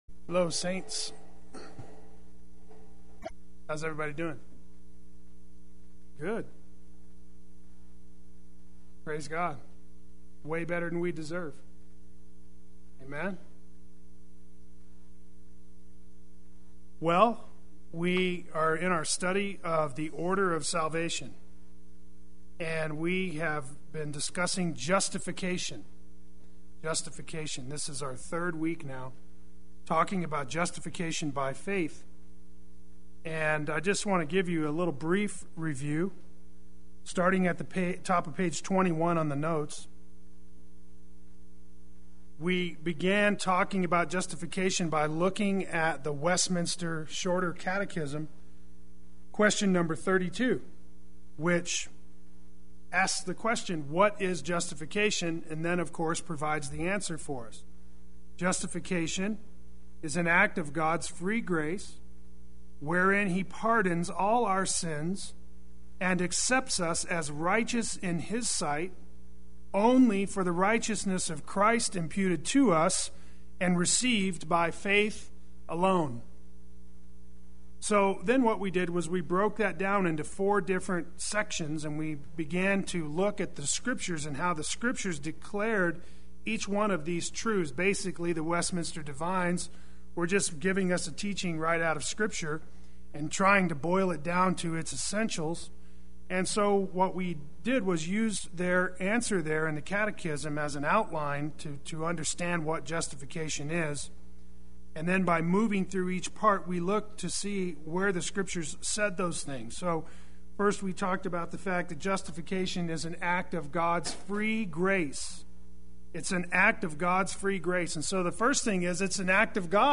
Play Sermon Get HCF Teaching Automatically.
Part 3 Wednesday Worship